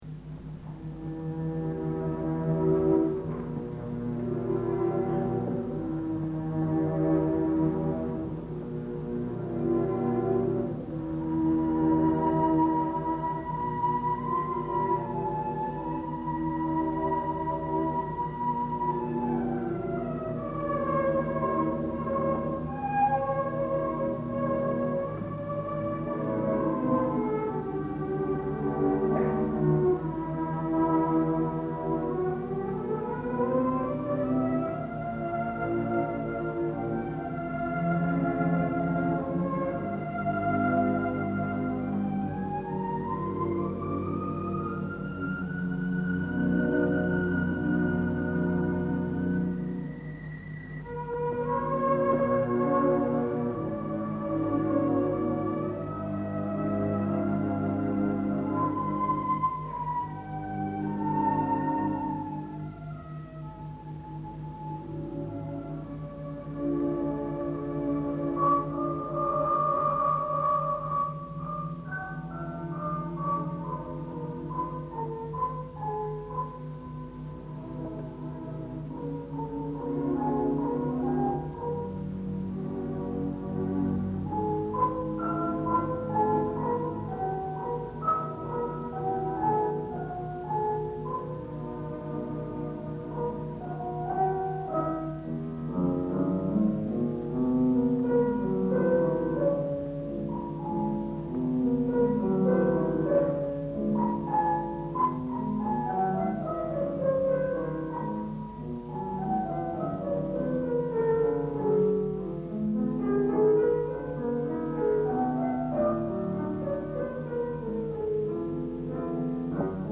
con l’esecuzione del primo concerto di D. Shostakovic op. 35 per pianoforte, tromba ed archi.